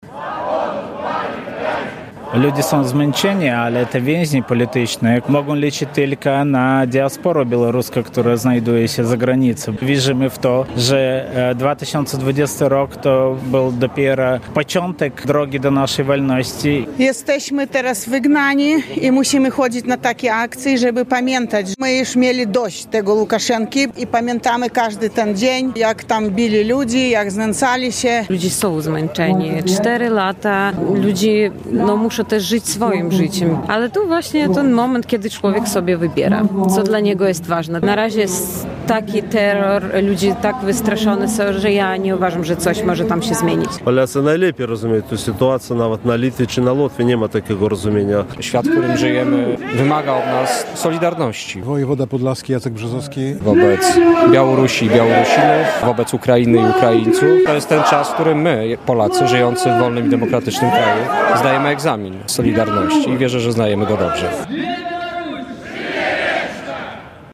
W 4. rocznicę sfałszowanych wyborów prezydenckich w Białorusi, w centrum Białegostoku diaspora białoruska zorganizowała wiec Godności.